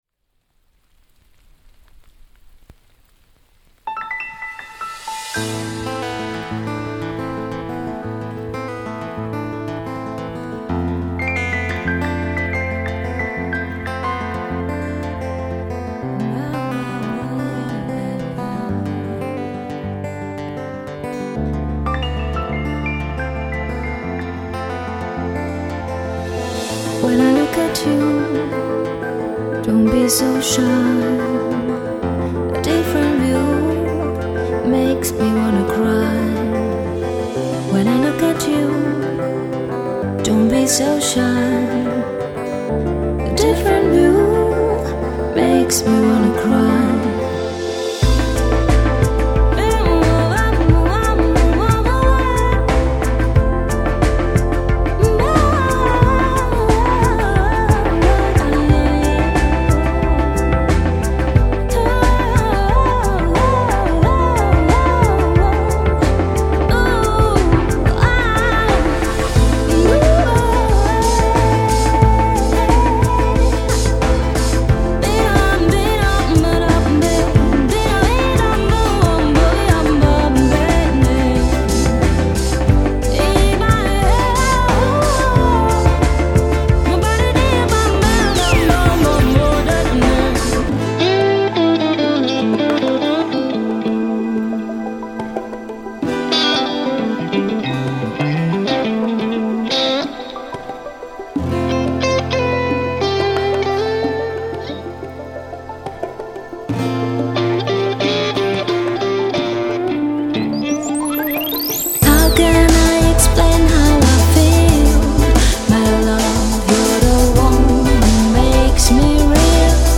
・Chillout：90BPM
Chillout